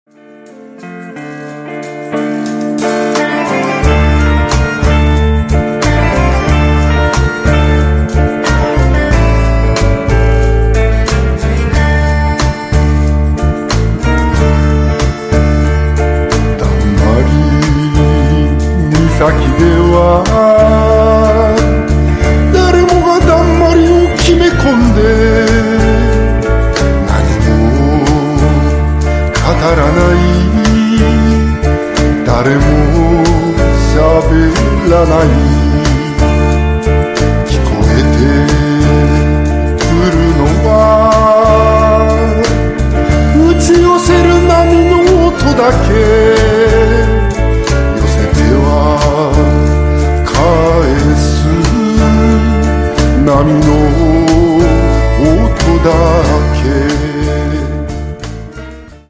サウンド的には数年前よりはまっているというエチオピアサウンドの影響あり。
ボーカル、ギター、ハーモニカ、カズー